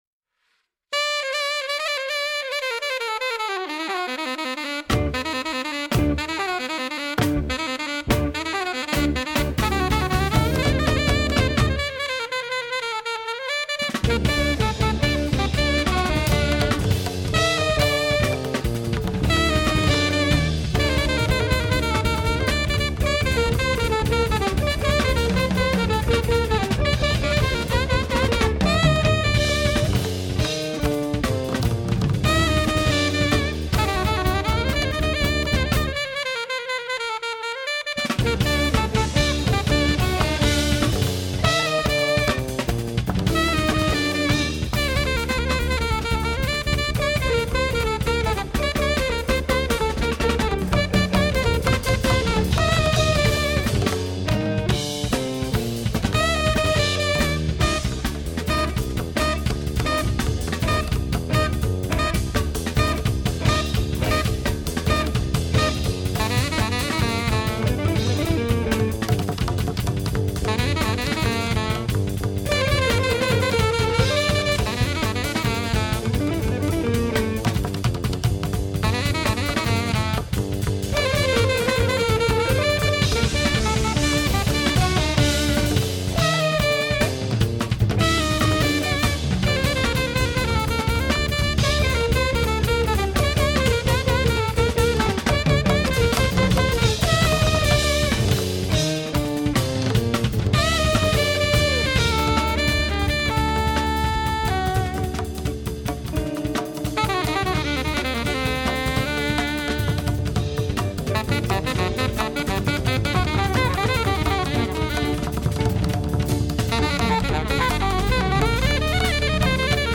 Indian alto saxophone player
which mixes Indian music ideas with electronic sounds.